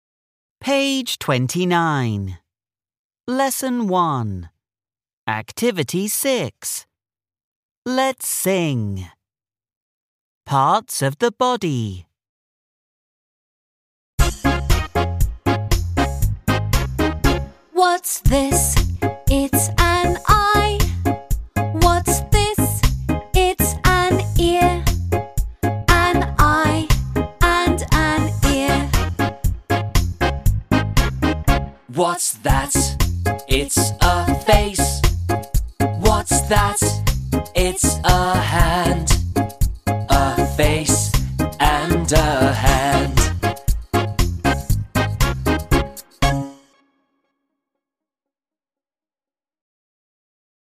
6 (trang 29 Tiếng Anh lớp 3 Global Success): Let’s sing. (Cùng hát)